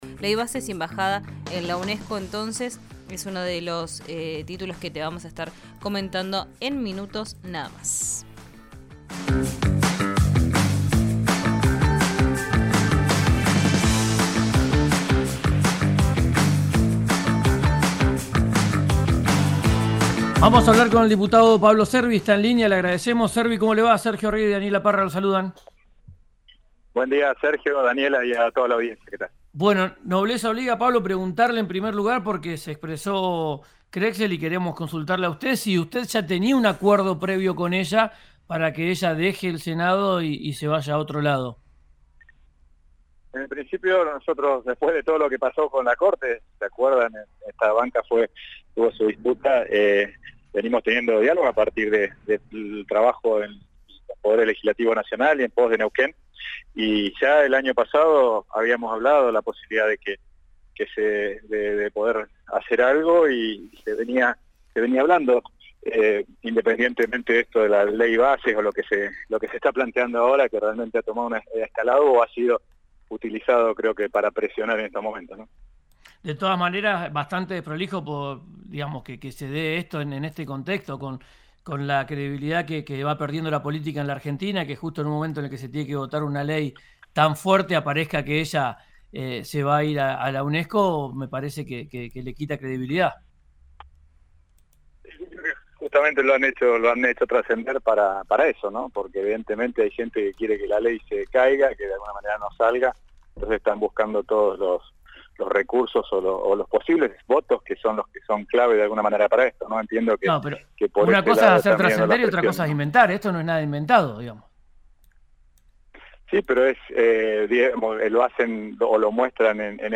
El diputado nacional de la UCR por Neuquén ocuparía la banca de la senadora y habló en RÍO NEGRO RADIO sobre la polémica de la designación.
Escuchá al diputado Pablo Cervi en RÍO NEGRO RADIO